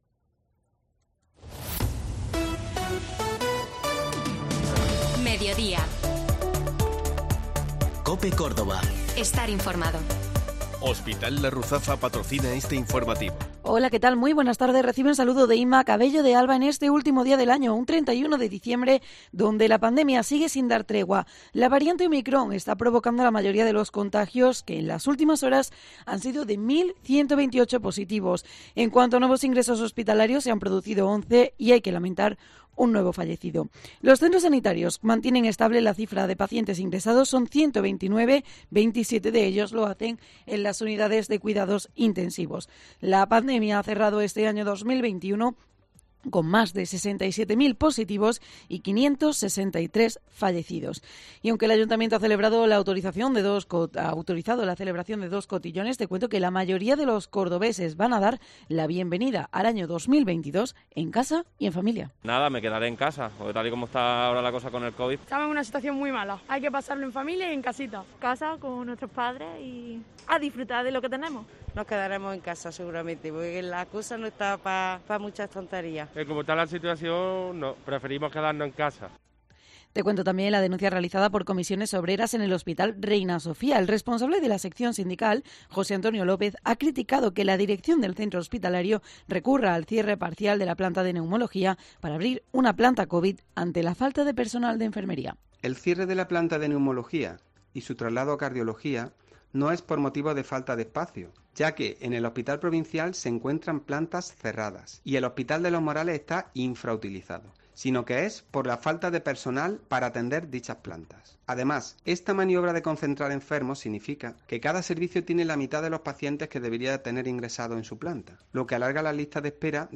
Informativo Mediodía COPE Córdoba